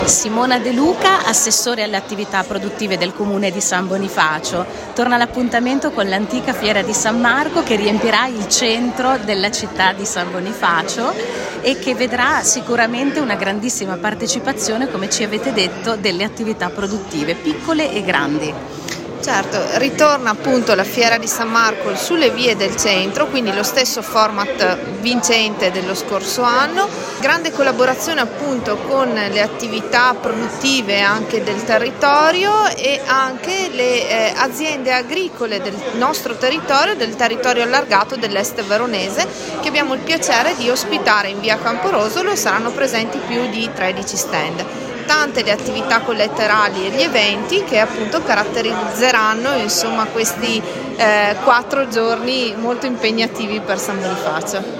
Al nostro microfono:
Simona De Luca, assessore alle attività produttive del comune di San Bonifacio